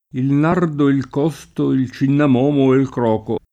cinnamomo [©innam0mo] (ant. o poet. cinnamo [©&nnamo]) s. m. — nome grecizzante di un genere di piante aromatiche: Il nardo, il costo, il cinnamomo e ’l croco [